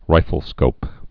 (rīfəl-skōp)